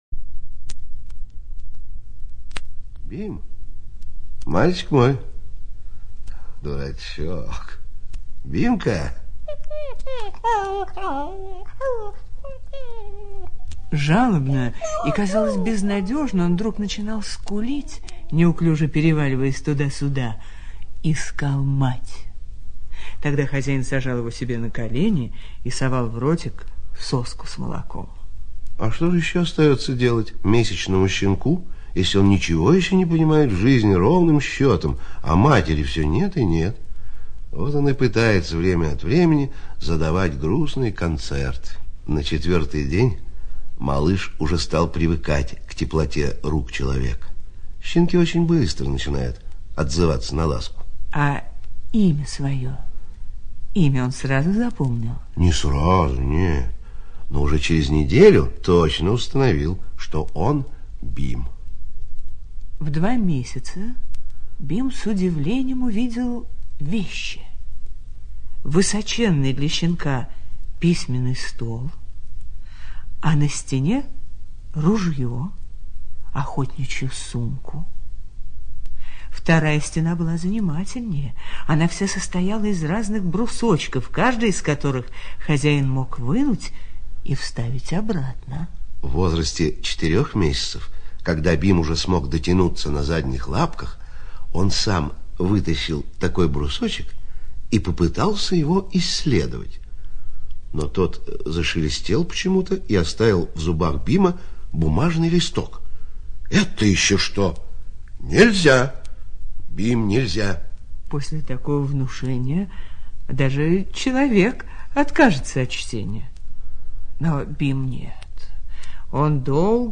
Белый Бим Черное ухо - аудио рассказ Троепольского Г.Н. Трогательная история про умного и интеллигентного шотландского сеттера.